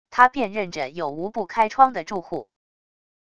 他辨认着有无不开窗的住户wav音频生成系统WAV Audio Player